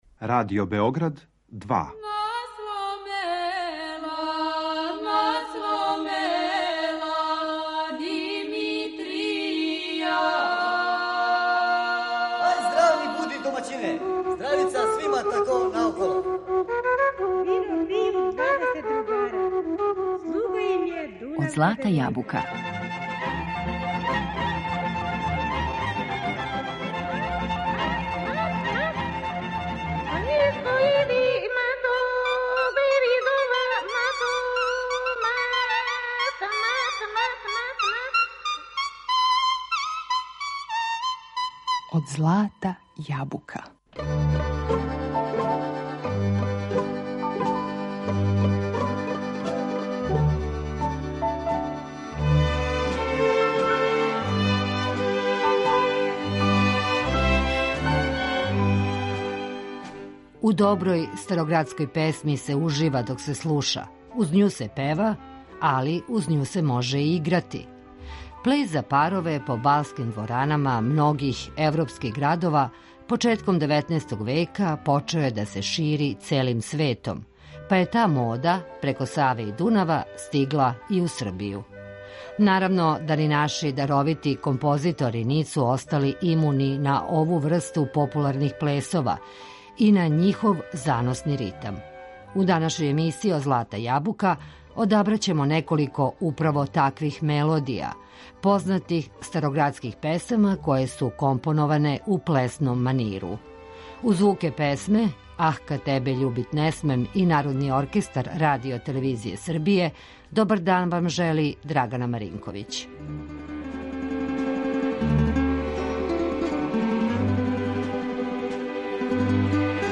Староградске песме у плесном маниру